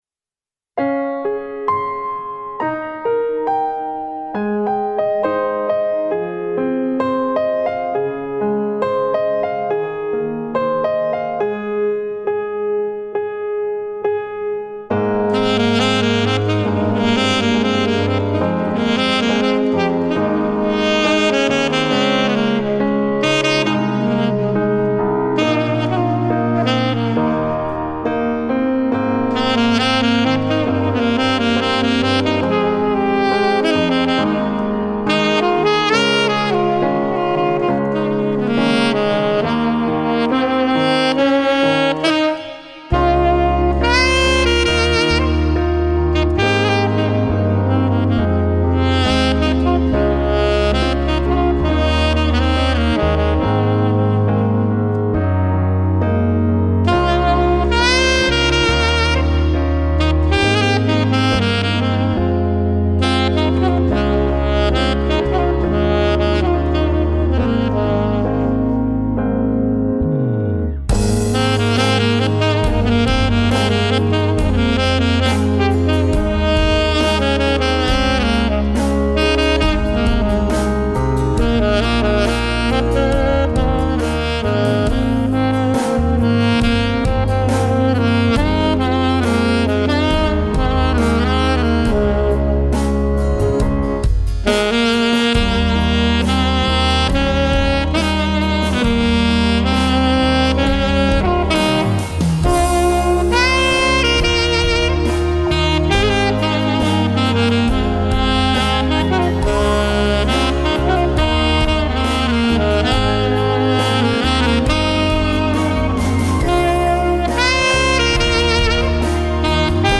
3周前 纯音乐 6